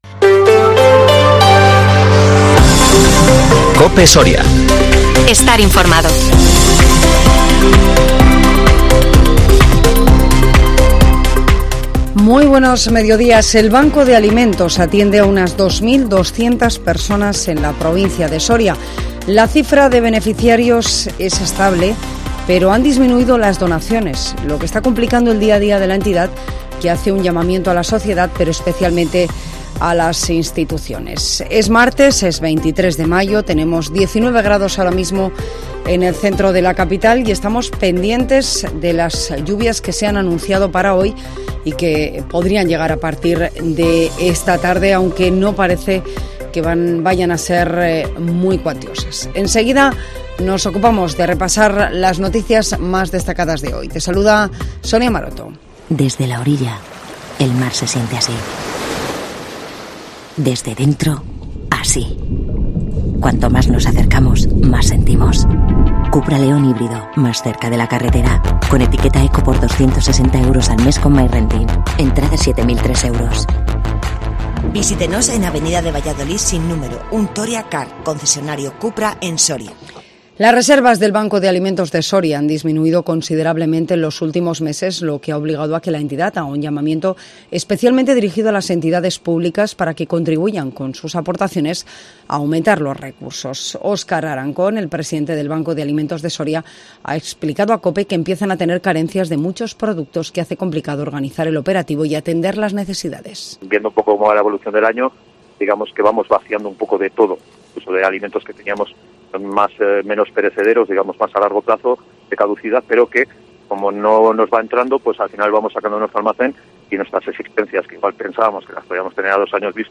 INFORMATIVO MEDIODÍA COPE SORIA 23 MAYO 2023